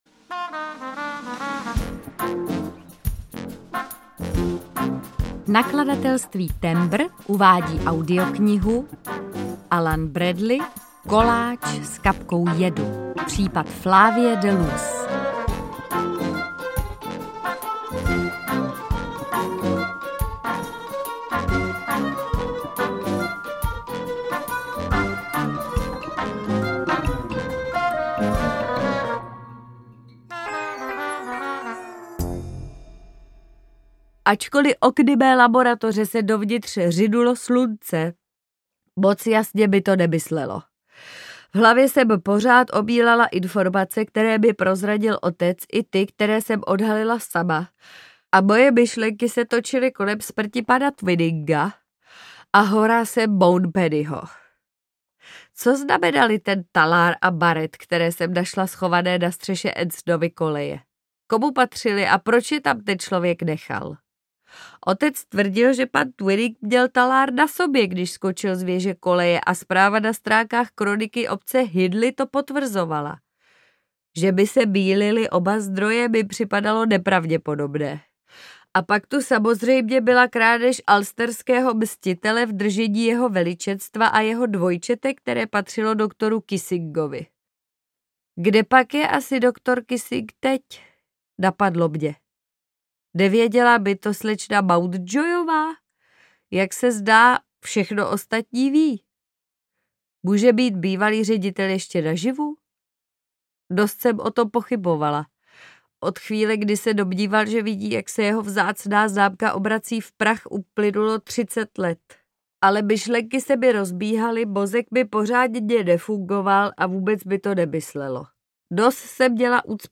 Koláč s kapkou jedu audiokniha
Ukázka z knihy
• InterpretMartha Issová